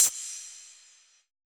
UHH_ElectroHatC_Hit-33.wav